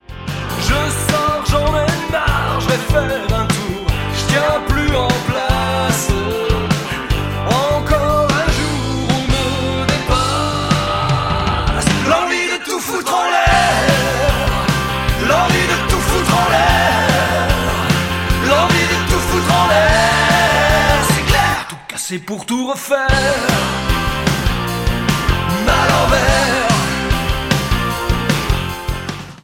Basse et chœurs
Batterie, chœurs et percussions
Chant et guitares électriques
Orgue